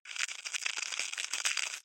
Звуки божьей коровки
Божья коровка шелестит в спичечной коробке